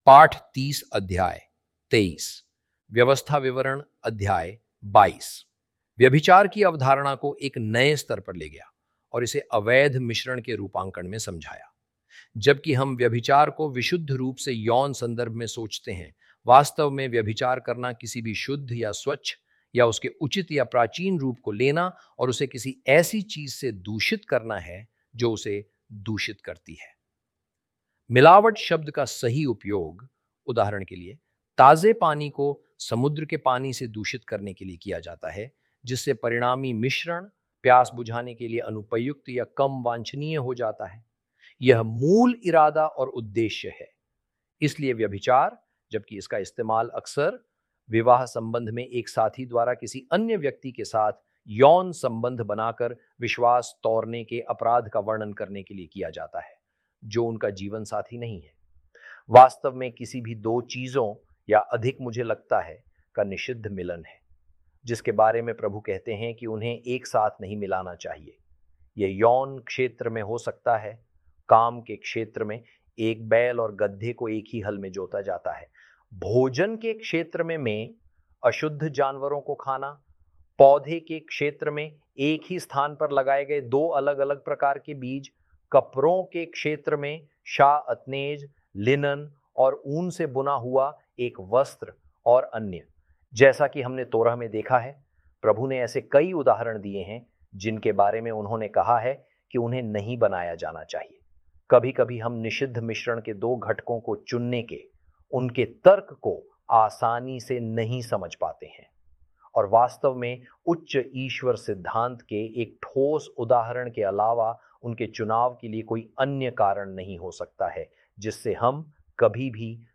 hi-audio-deuteronomy-lesson-30-ch23.mp3